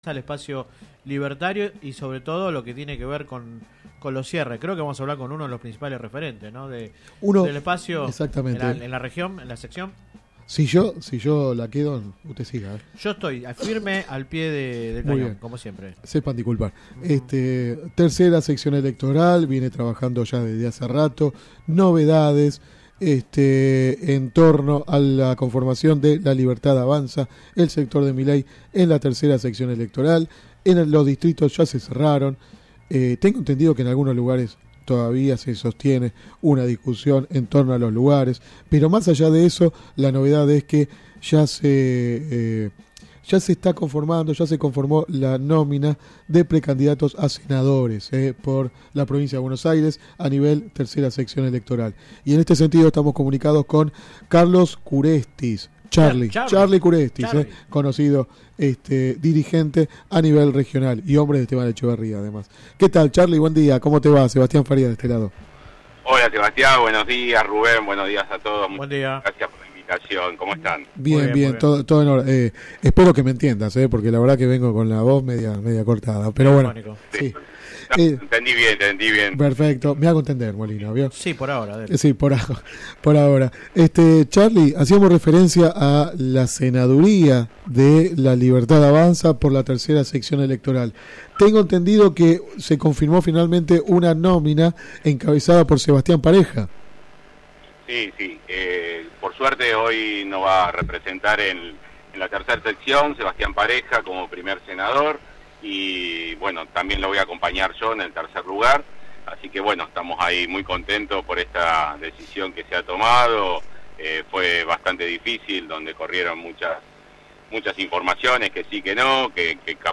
entrevista radial